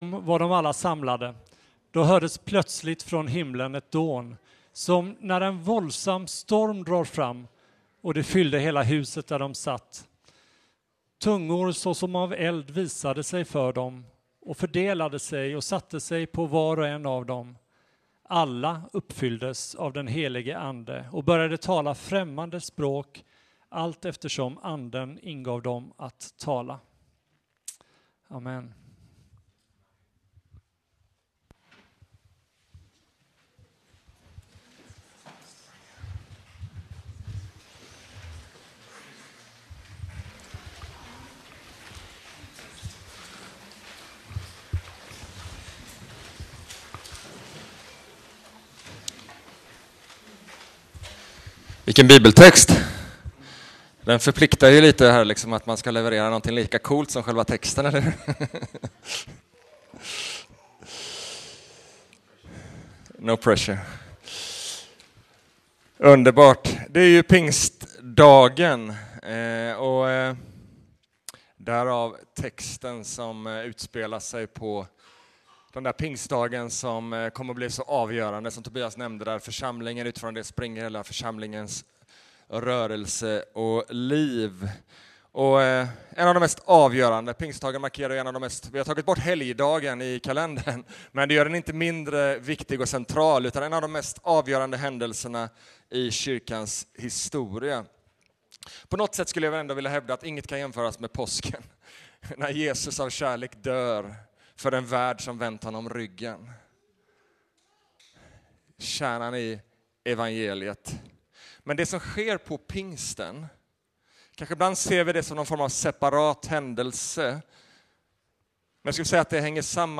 Launch Sermon Player Pingstdagen 2025-06-08 Leva livet med den Helige Ande: Vem har Anden?